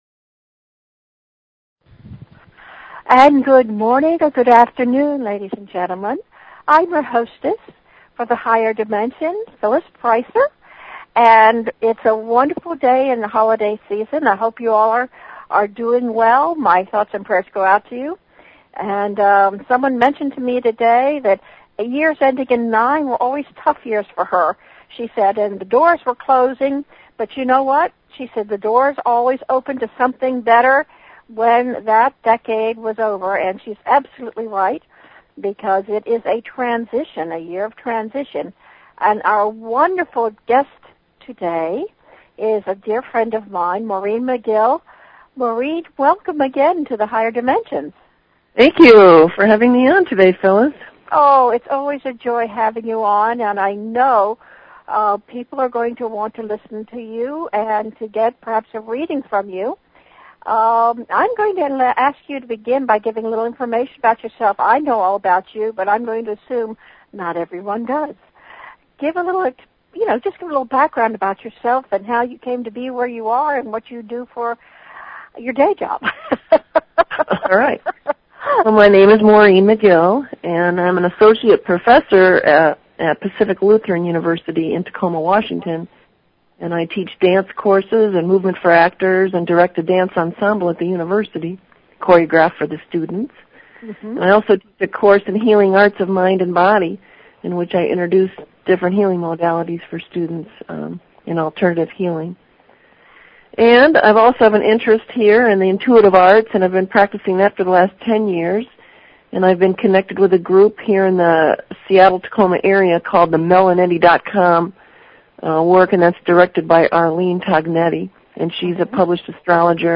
Talk Show Episode, Audio Podcast, Higher_Dimensions and Courtesy of BBS Radio on , show guests , about , categorized as